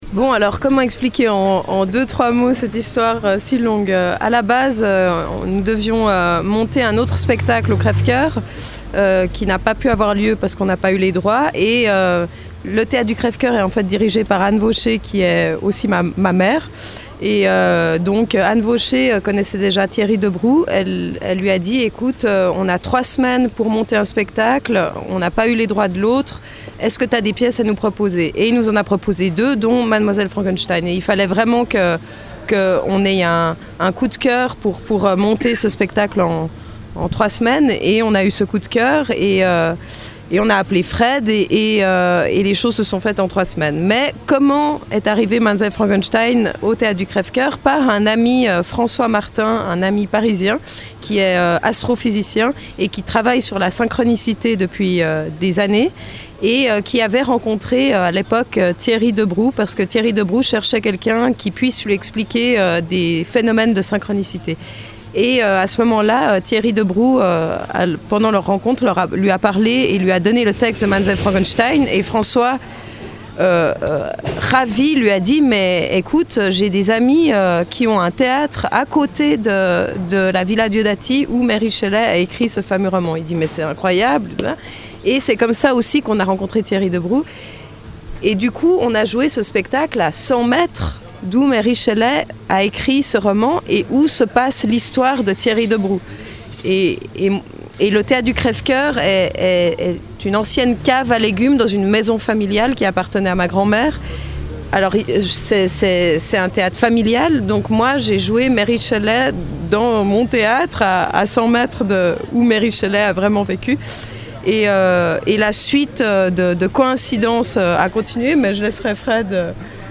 comédiens